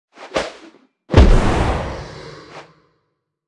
Media:anim_eltigre_farawayroar_01.wav 动作音效 anim 查看其技能时触发动作的音效
Anim_eltigre_farawayroar_01.wav